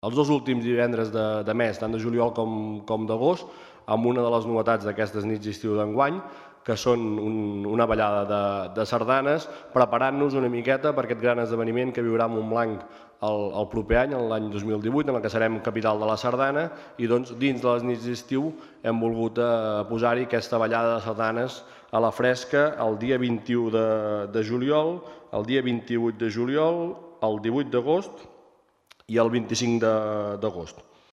ÀUDIO: El regidor de cultura, Jordi Albalat, avança les novetats de les Nits d’Estiu